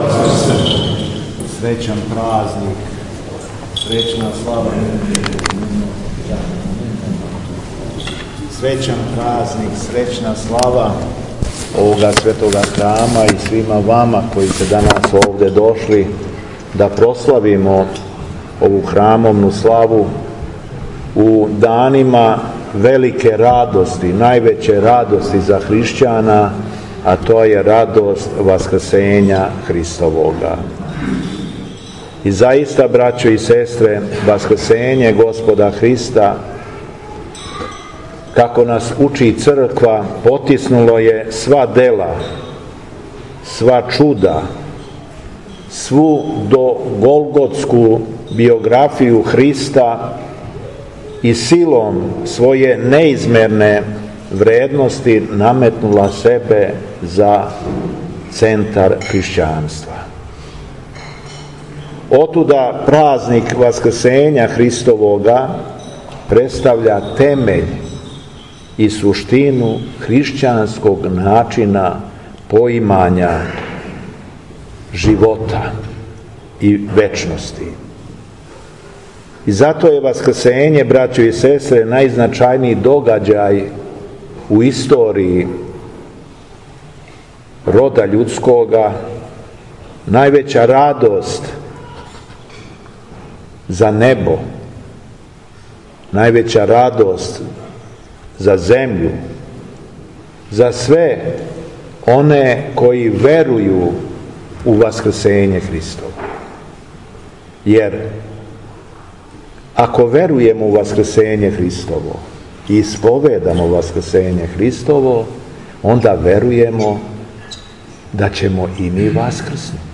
У другу недељу по Васкрсењу Христовом када се молитвено сећамо жена Мироносица и Светог јеванђелисте Марка, 8. маја 2022. године, Његово Преосвештенство Епископ шумадијски Господин Јован служио је Свету Архијерејску Литургију у храму који је посвећен женама Мироносицама у селу Винчи надомак Тополе....
Беседа Његовог Преосвештенства Епископа шумадијског г. Јована